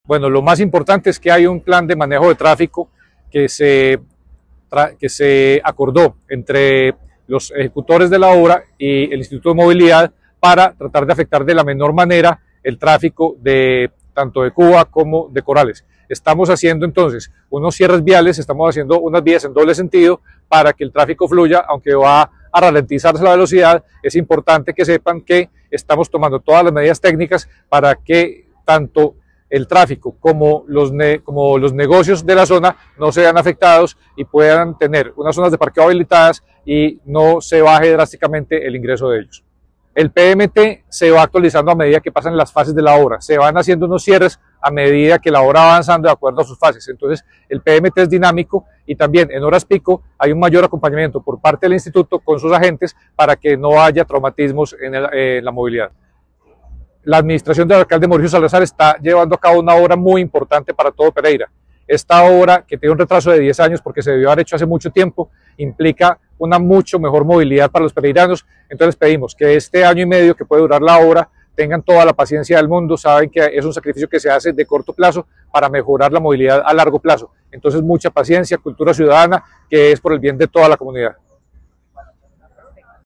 La Secretaria de Infraestructura Diana Osorio Bernal explicó las medidas que se implementarán desde el lunes 02 de febrero de 2026.